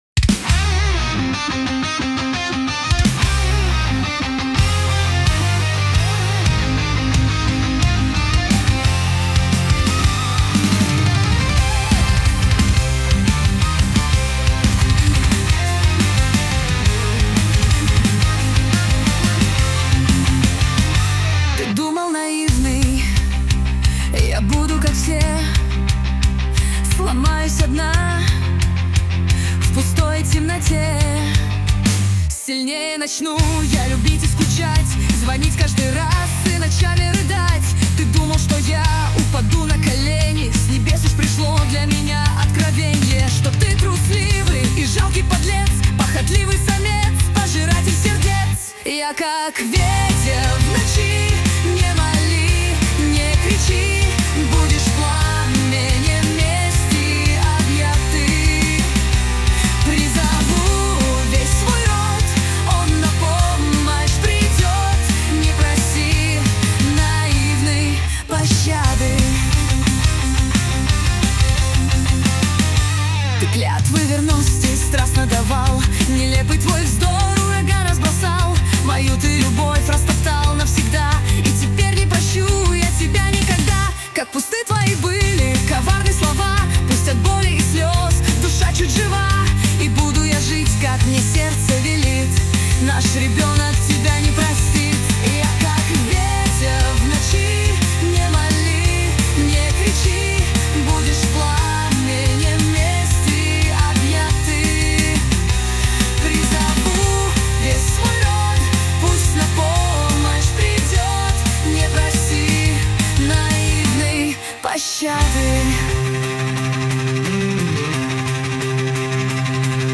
Music and vocals generated via AI tools
Музыка и вокал сгенерированы с помощью искусственного интеллекта
• Исполняет: V4.5 Fusion
• Жанр: AI Generated